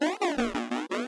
Charamario_DonkeyKongJr_walk2.wav